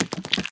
sounds / mob / spider / step2.ogg